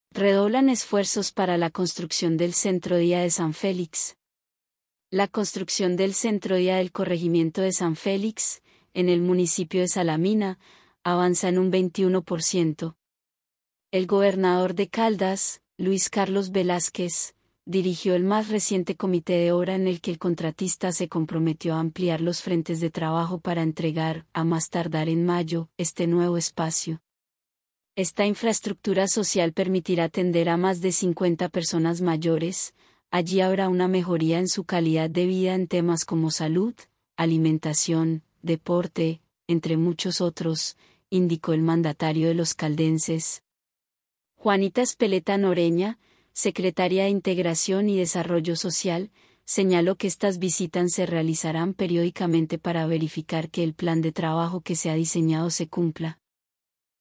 Audio Noticia – Gobernación de Caldas